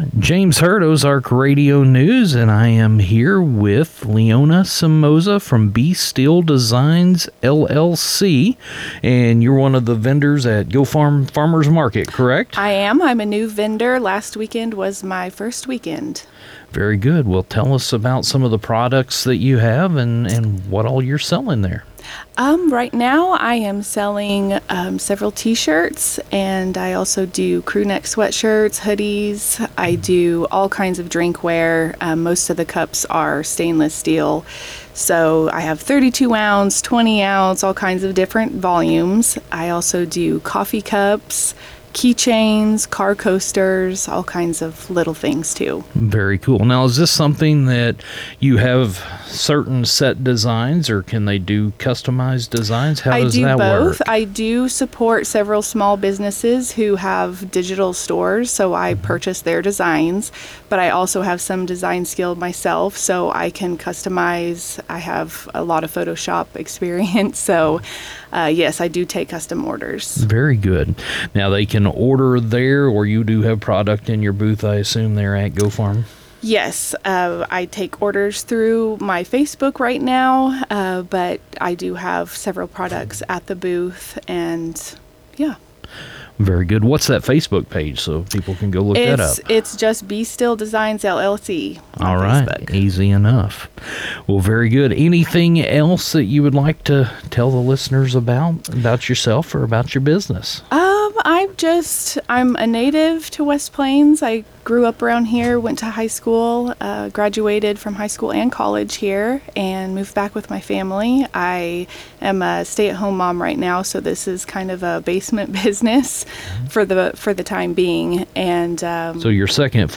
BE-STILL-DESIGN-INTERVIEW.wav